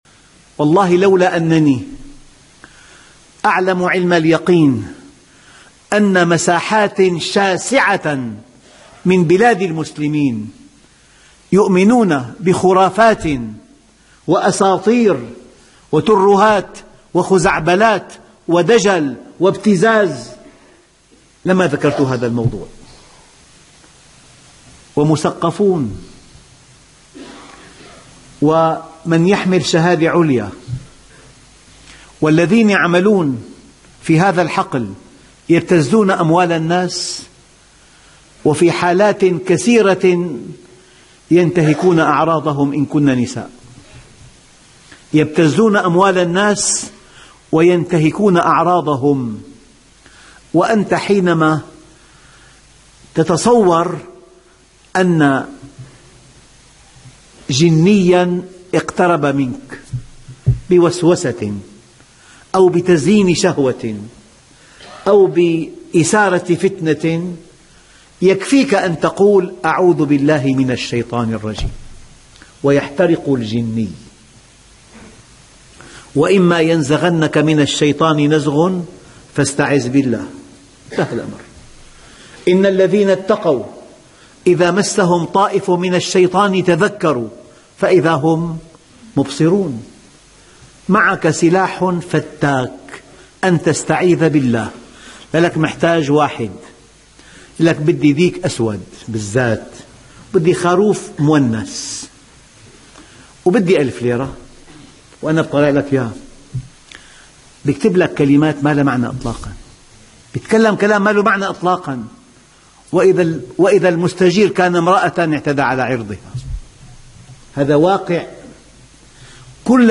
كفانا خُرافات وخُزعبلات ........ درس هاااام ........